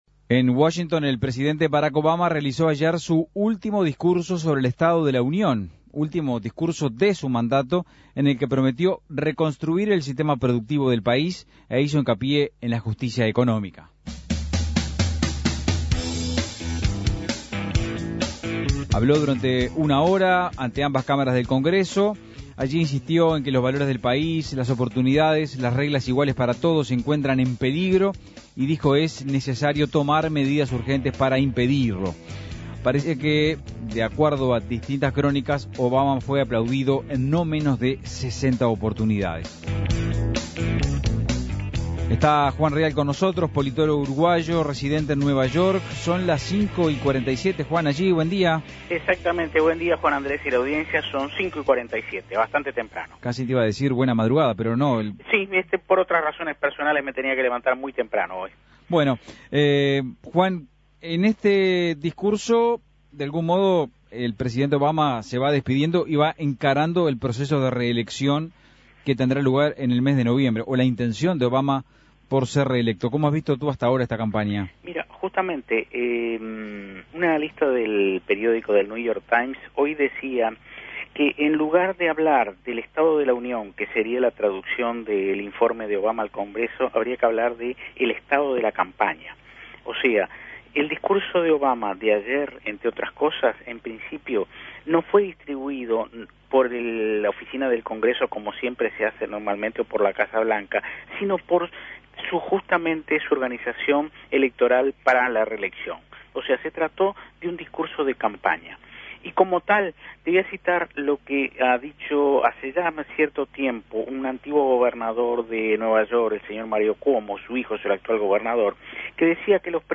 desde Nueva York.